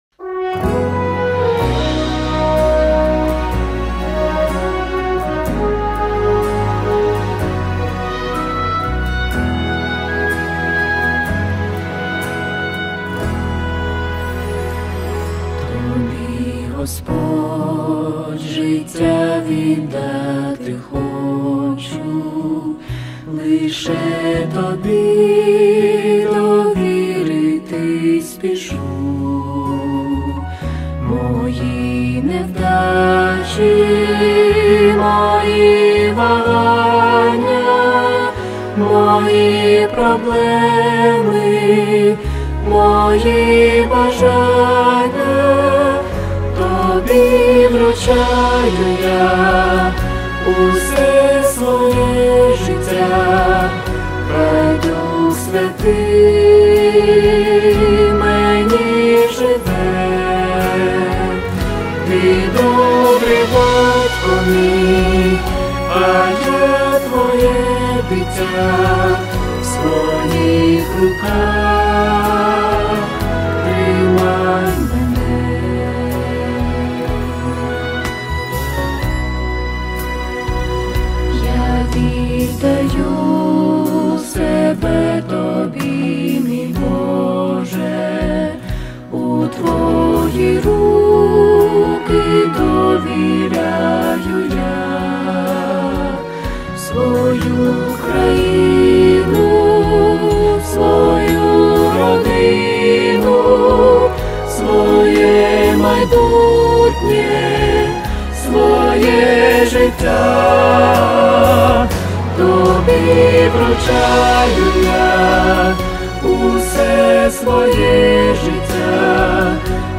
Worship Songs
5177 просмотров 605 прослушиваний 68 скачиваний BPM: 62